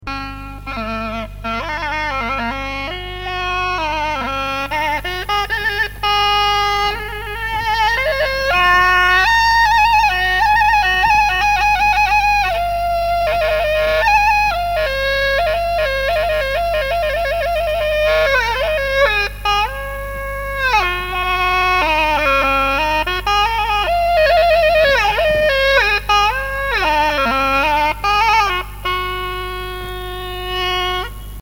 ปี่ไหน เป็นเครื่องเป่าประเภทดำเนินทำนอง มีลักษณะเช่นเดียวกับ ปี่่นอก ปี่่กลาง และปี่ในของภาคกลาง แต่มีขนาดเล็กกว่า มีระดับเสียงสูงกว่า มีรูบังคับเสียง ๖ รู ทำด้วยไม้เนื้อแข็ง เช่นไม้ประดู่ ลิ้นปี่ทำด้วยใบตาลผูกติดกับกำพวด(ท่อกลม เล็ก) ใช้การประกอบการแสดงโนราและหนังตะลุง
ปี่ เป็นเครื่องเป่าเพียงชิ้นเดียวของวง นิยมใช้ปี่ใน หรือ บางคณะอาจใช้ปี่นอก ใช้เพียง ๑ เลา ปี่มีวิธีเป่าที่คล้ายคลึงกับขลุ่ย ปี่มี ๗ รูแต่สามารถกำเนิดเสียงได้ ถึง ๒๑ เสียงซึ่งคล้ายคลึงกับเสียงพูด มากที่สุด
เสียงpekrng.mp3